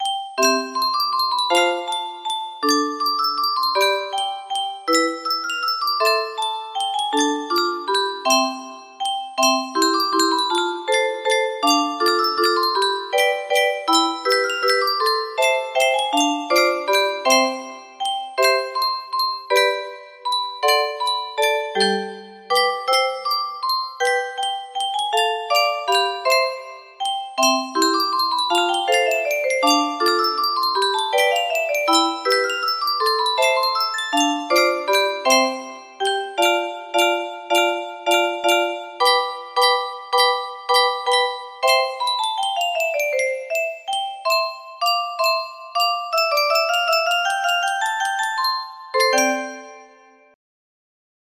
Clone of We wish you a merry christmas_F scale80 music box melody
Grand Illusions 30 (F scale)